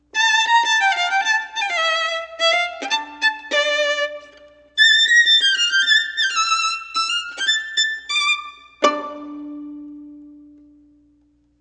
violin.wav